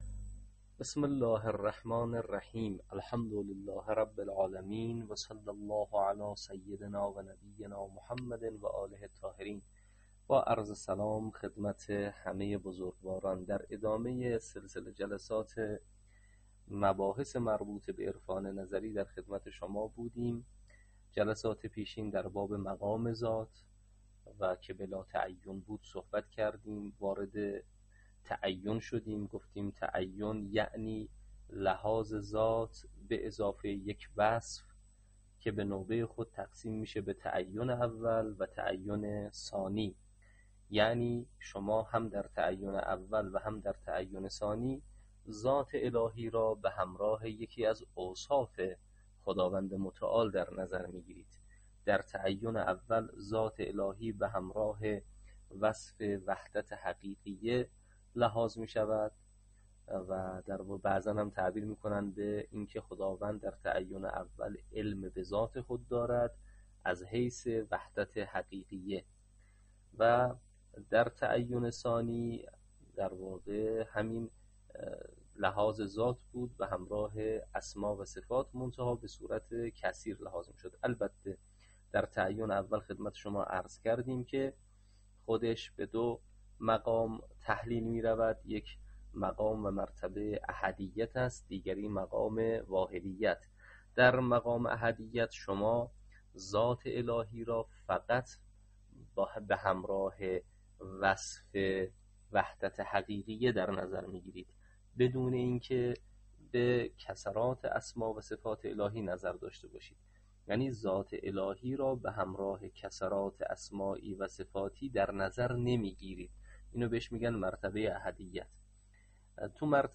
تدریس عرفان نظری